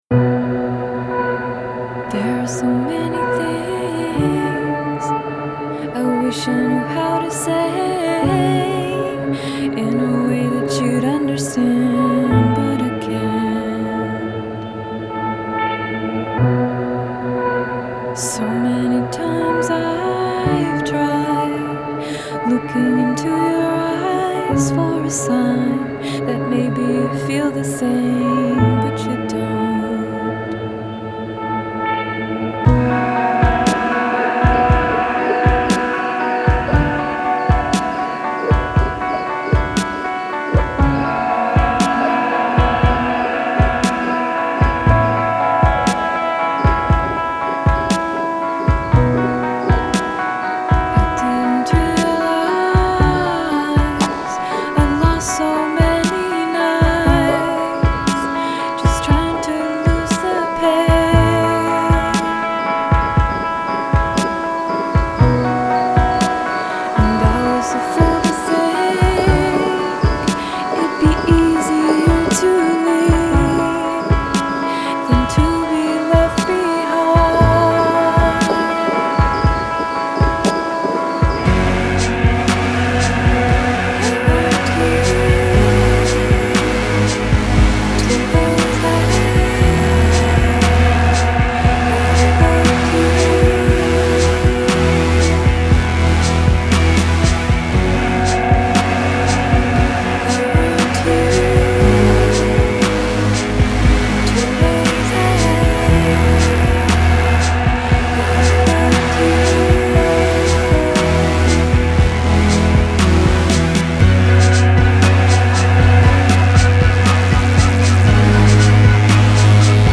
O trio novaiorquino